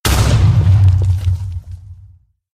explode.ogg